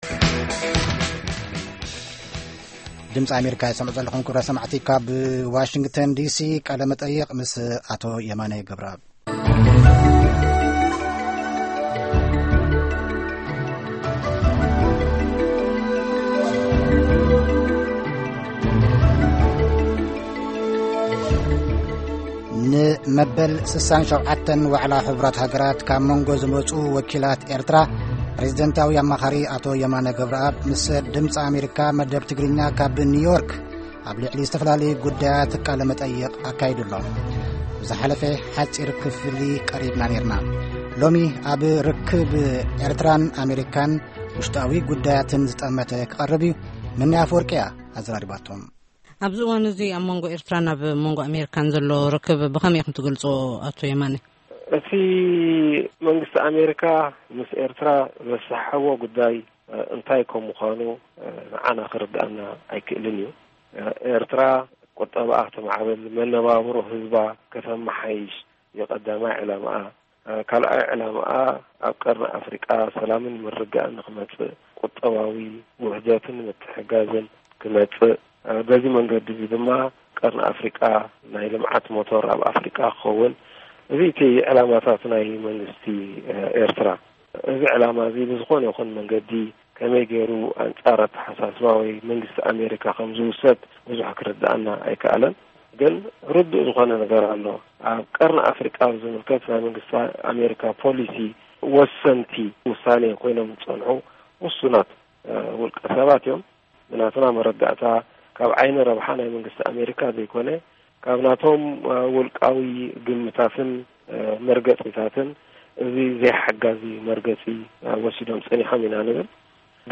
ቃለ- መጠይቅ - ምስ አቶ የማነ ገብረአብ አማኻሪ ፕረዚደንት ኤርትራ -2ይ ክፋል